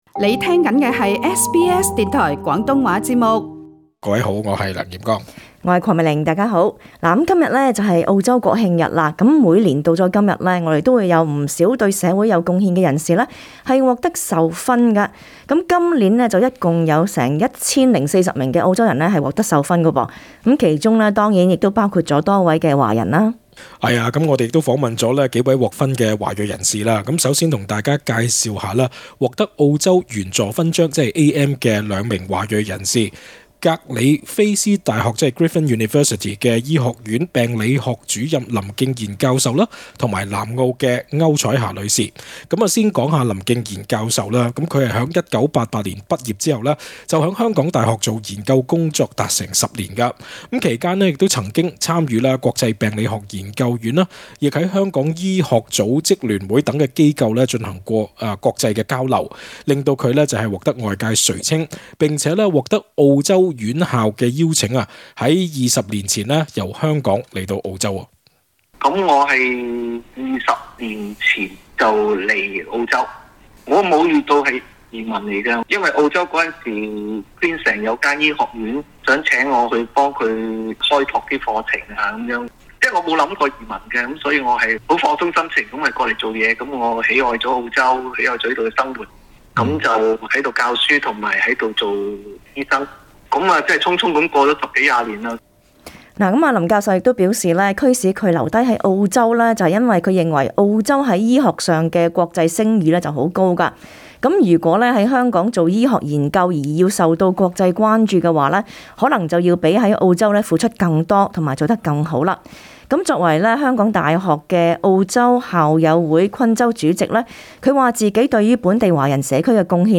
SBS 廣東話節目亦訪問了多位獲得授勳的華裔人士。